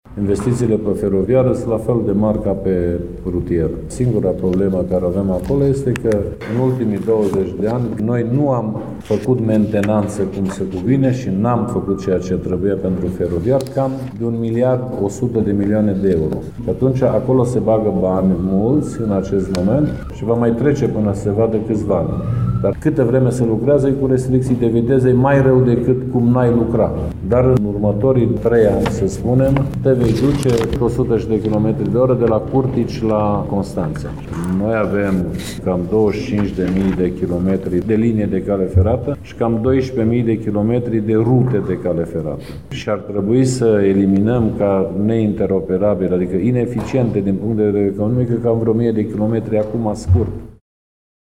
Cu finanţare proprie şi europeană sunt în curs ample lucrări de refacere a reţelei, care însă vor mai dura 3 sau 4 ani, a declarat astăzi, la Cluj-Napoca, ministrul Transporturilor, Ioan Rus: